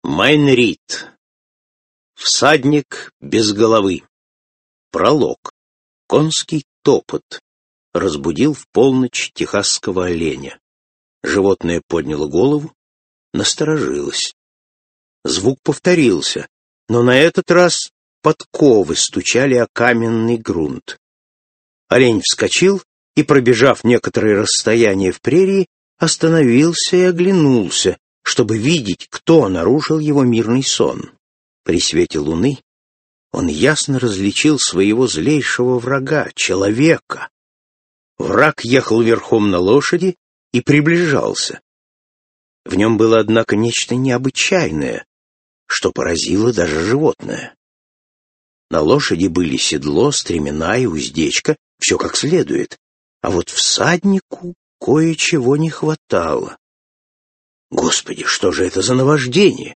Аудиокнига Всадник без головы | Библиотека аудиокниг